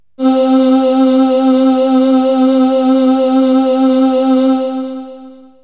VOX_CHORAL_0004.wav